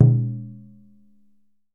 DJUN DJUN00L.wav